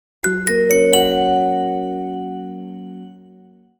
Примеры звуковых логотипов